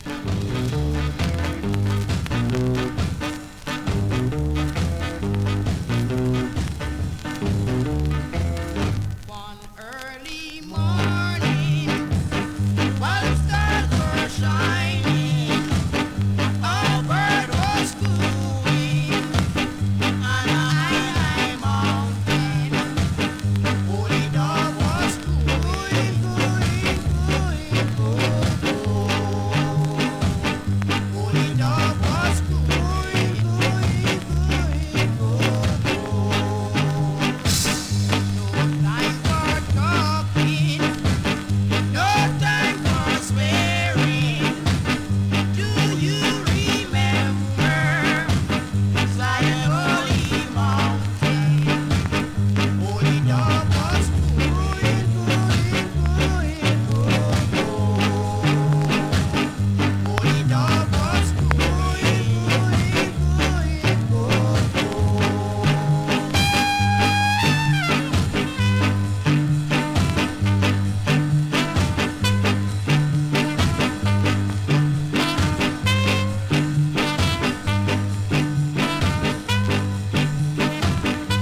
SKA!!
スリキズ、ノイズそこそこありますが